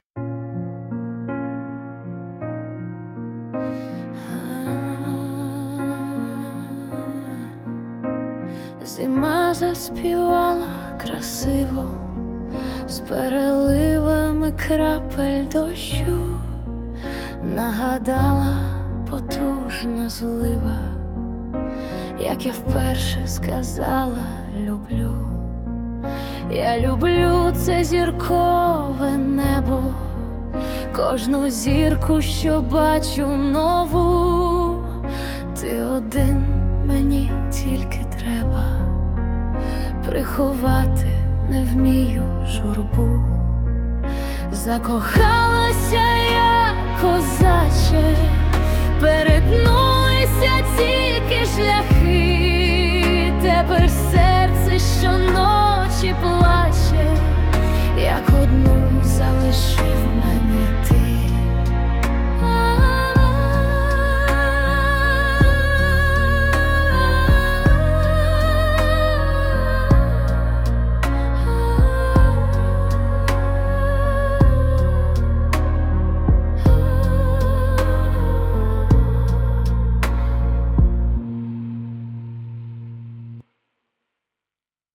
Музичний супровід створено за допомогою SUNO AI